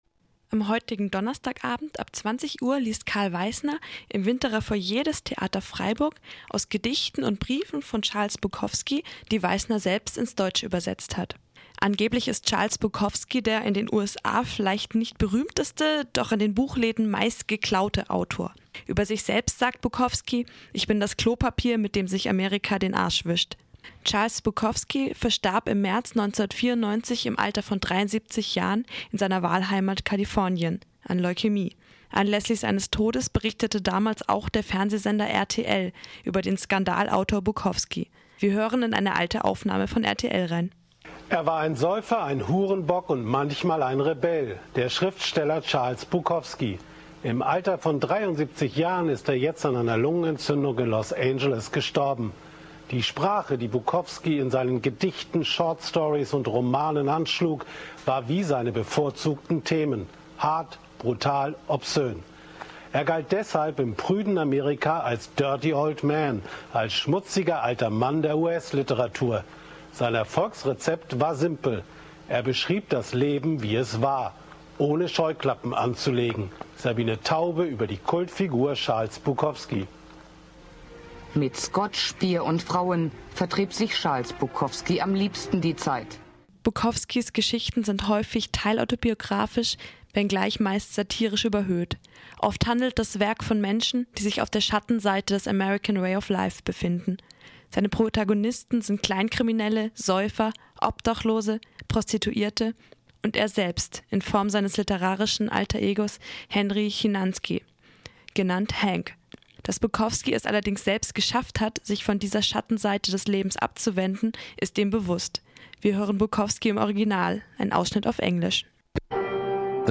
Mit einem Ausschnitt aus deutschen Fernsehnachrichten über seinen Tod und einem O-Ton von Bukowski selbst.